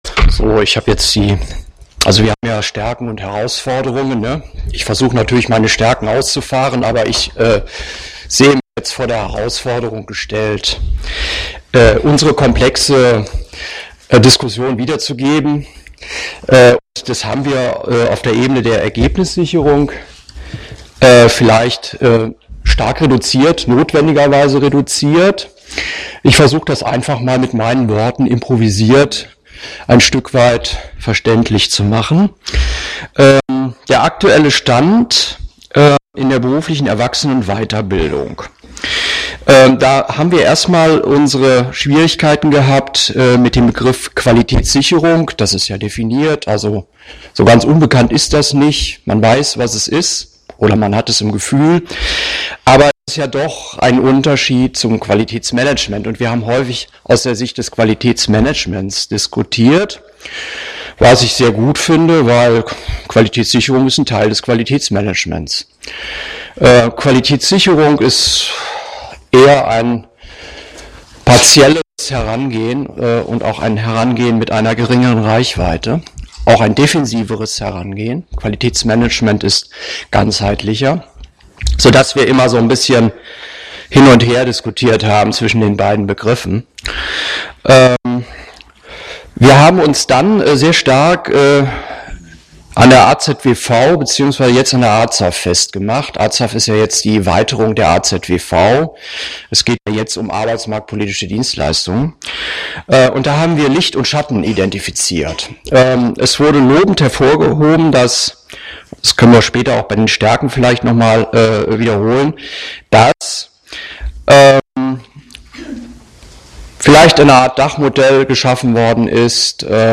Veranstaltungstag Präsentation und Diskussion der Ergebnisse aus den Foren 1 - 5 im Plenum Präsentation der Forenergebnisse Zusammenfassende Darstellungen der Ergebnisse aus ...